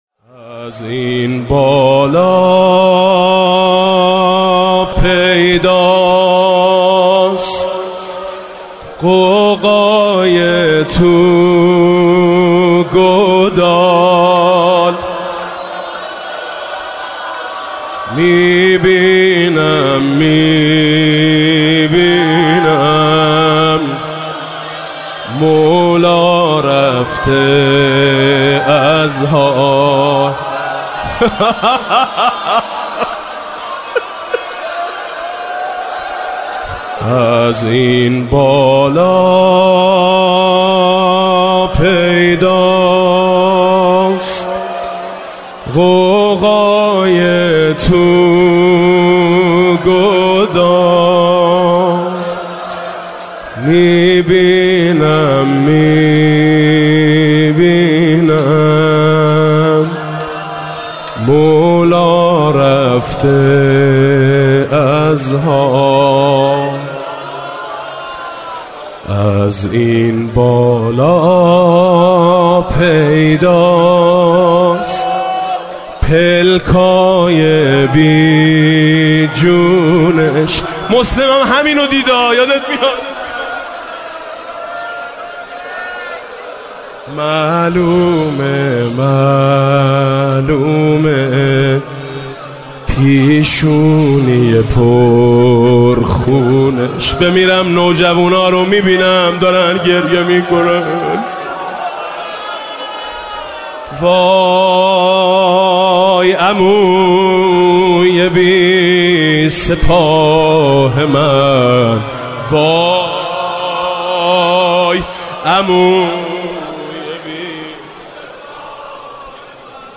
دانلود یکجا دانلود مراسم شب اول محرم ۹۸ به صورت یکجا به اذن فاطمه… – Read More.